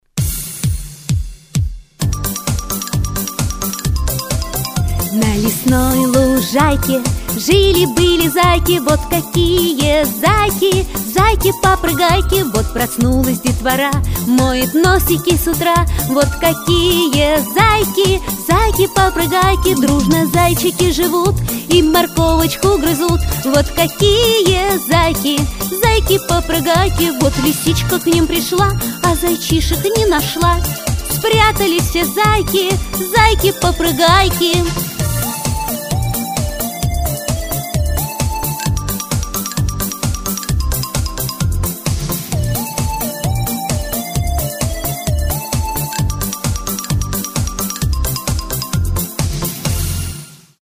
Музыкальная игра для малышей. Все движения выполняются по тексту песенки (на усмотрение муз. руководителя).